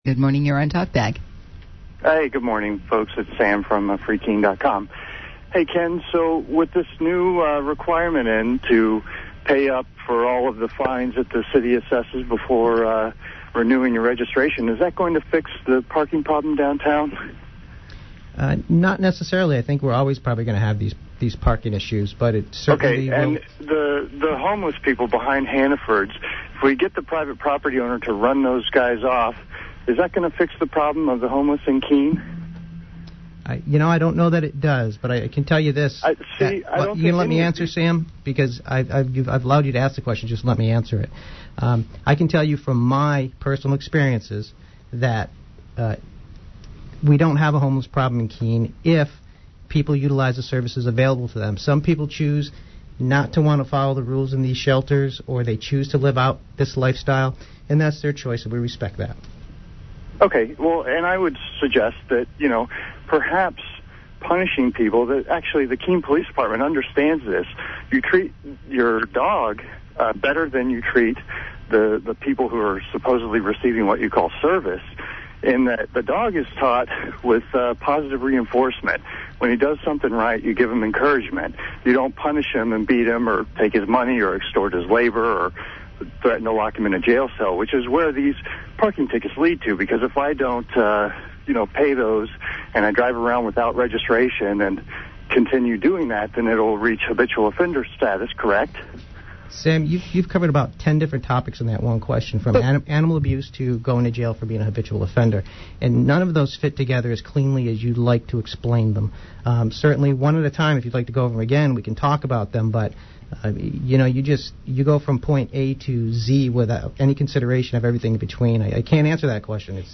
On last week’s “Talkback” on WKBK, liberty activists called to talk about parking, positive reinforcement, “disorderly houses”, and voluntary solutions. The smarmy Dartmouth guy also calls and proves he just hasn’t been paying attention.